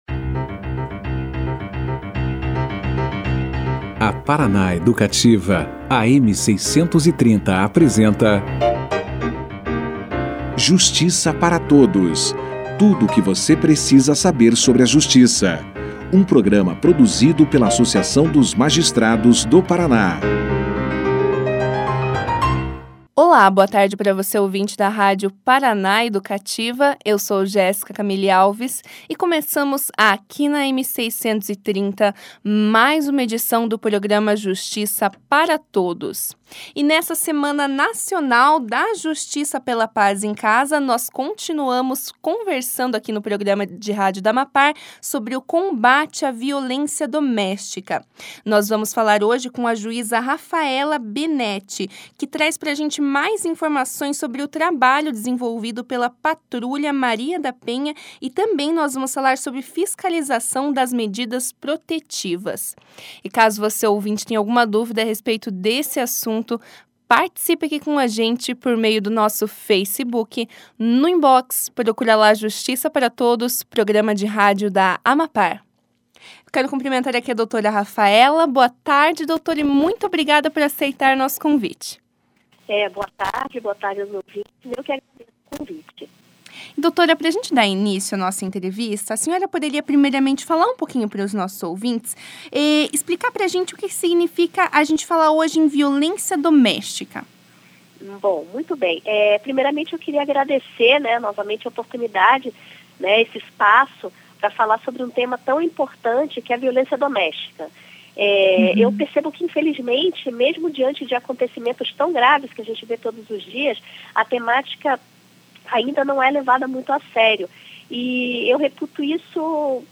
Nesta terça-feira (21), segundo dia da Semana Nacional da Justiça pela Paz em Casa, o Justiça para Todos conversou com a juíza Raphaella Benetti da Cunha Rios sobre o trabalho desenvolvido pela Patrulha Maria da Penha e a fiscalização de medidas protetivas. A magistrada deu início a entrevista falando sobre a importância do projeto Justiça pela Paz em Casa, como forma de ampliar a efetividade da Lei Maria da Penha e reverter a realidade da violência contra a mulher no Brasil. Durante a conversa, a juíza falou sobre a lei que criminaliza o descumprimento das medidas protetivas e quais são as possíveis punições.